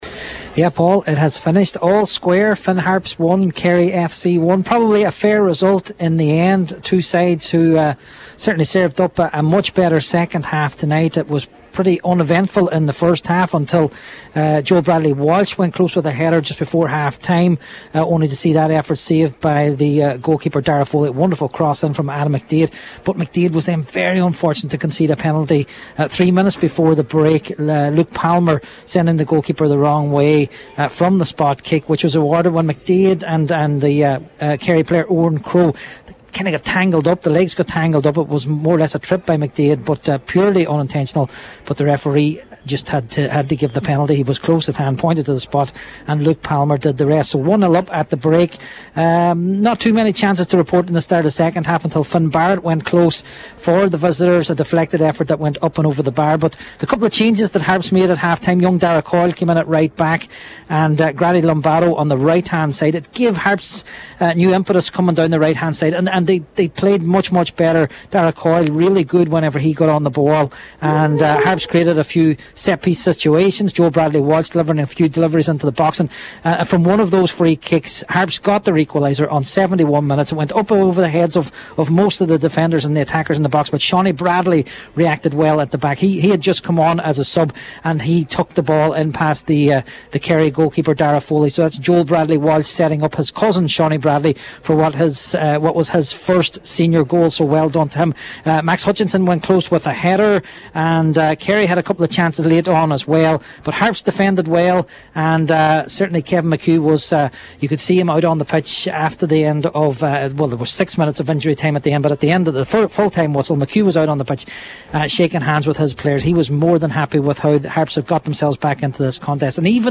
reports for Highland Radio Sport: